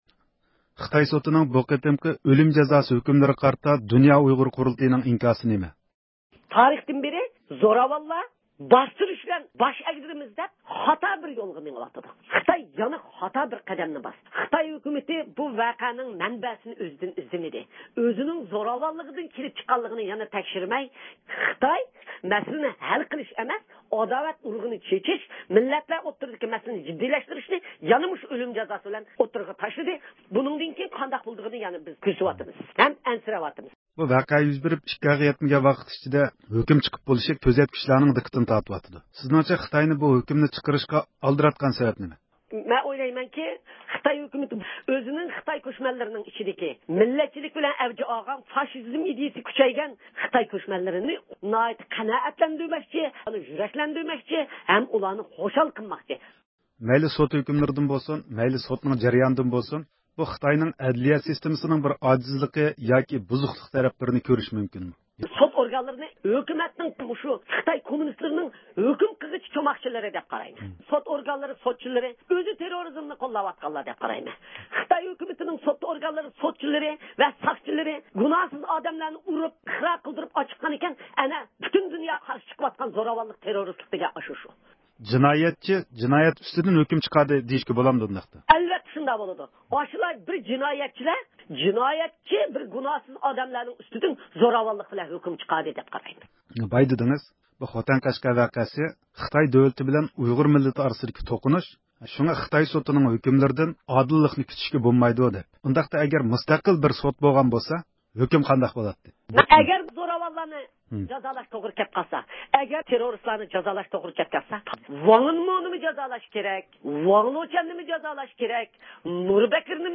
ئۇيغۇر مىللىي ھەرىكىتىنىڭ رەھبىرى رابىيە قادىر خانىم، بۈگۈن رادىئومىز زىيارىتىنى قوبۇل قىلىپ، خىتاي سوتىنىڭ خوتەن ۋە قەشقەر ۋەقەسىگە چېتىشلىق پىدائىيلارنى ئۆلۈمگە ھۆكۈم قىلغانلىقىغا قارىتا پىكىر بايان قىلدى.